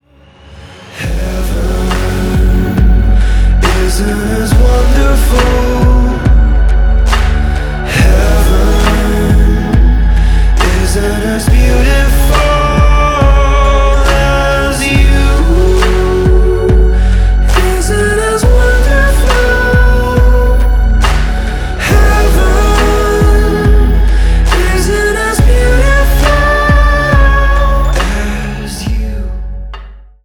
• Качество: 320, Stereo
поп
мужской вокал
атмосферные
спокойные
alternative pop
романтичные
эпичные